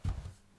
krok_06.wav